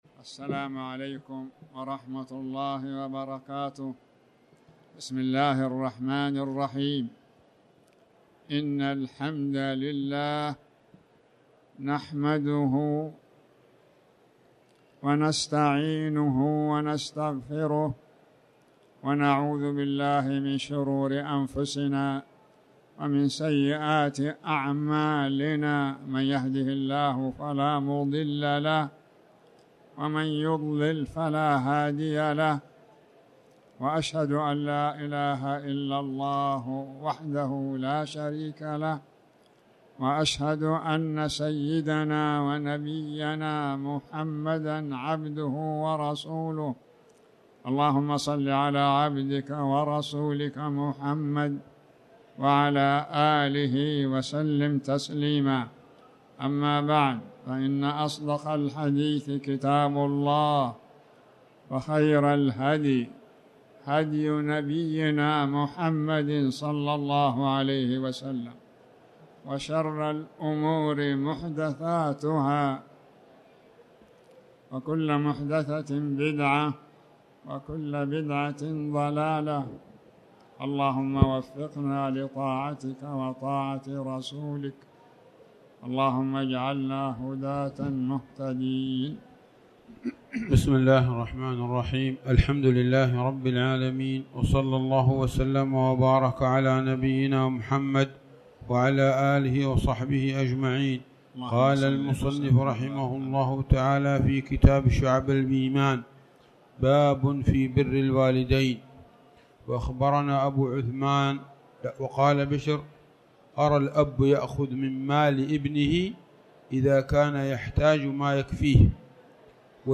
تاريخ النشر ١١ ذو القعدة ١٤٣٩ هـ المكان: المسجد الحرام الشيخ